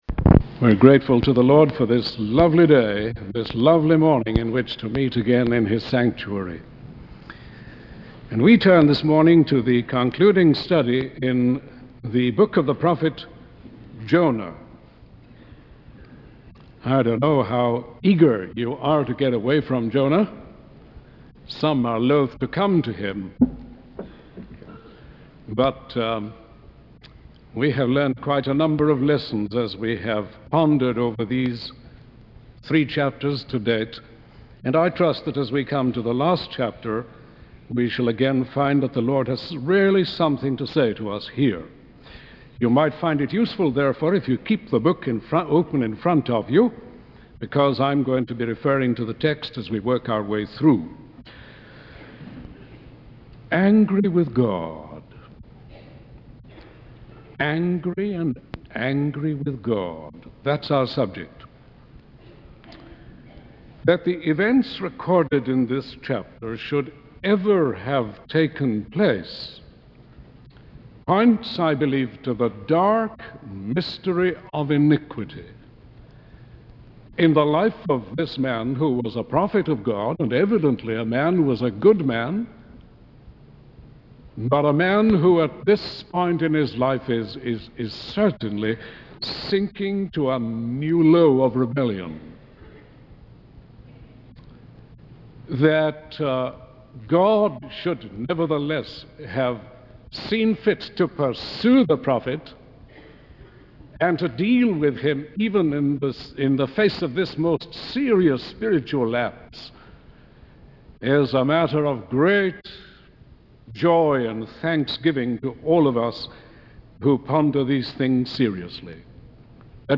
In this sermon, the preacher focuses on the story of Jonah and his rebellion against God. He highlights Jonah's desire to die and his grumbling because of his discomfort.